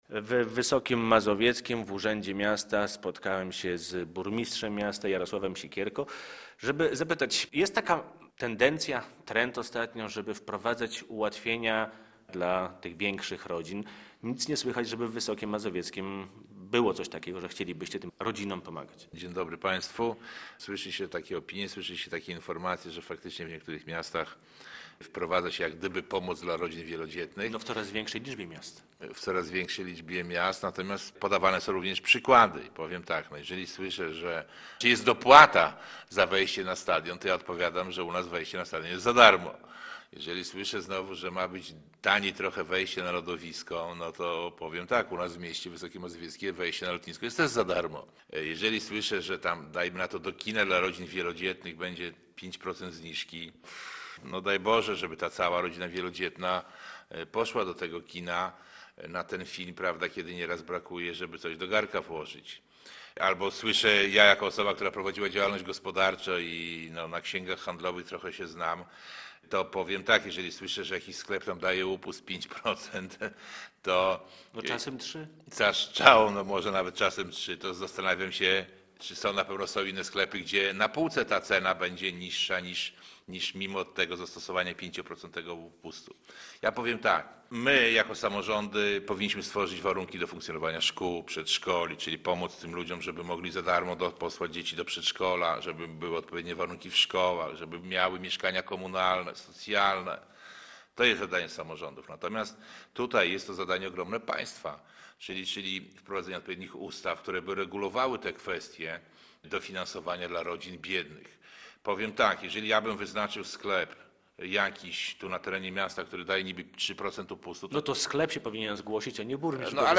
O Karcie Dużej Rodziny z burmistrzem Wysokiego Mazowieckiego Jarosławem Siekierko rozmawia